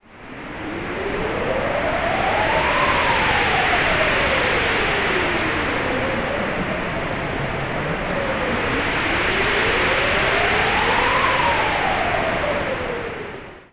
Wind Storm.wav